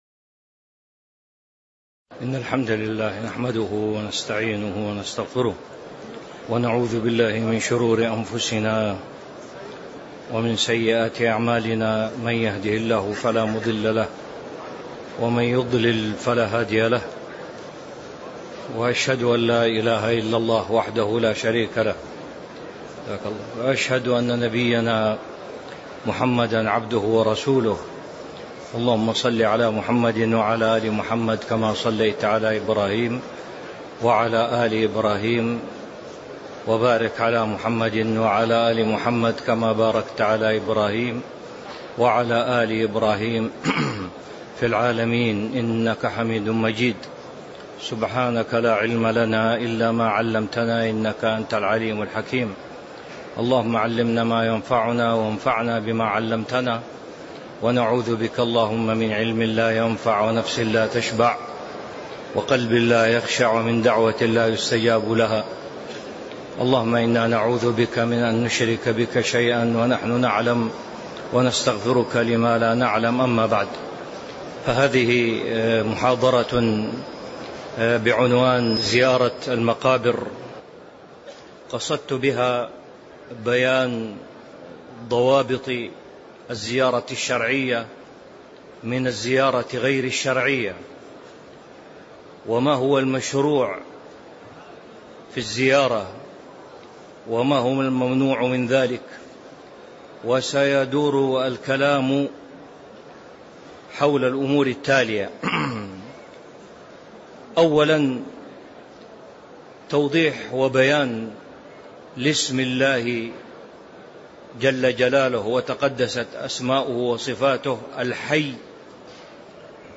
تاريخ النشر ١٤ ذو الحجة ١٤٤٥ هـ المكان: المسجد النبوي الشيخ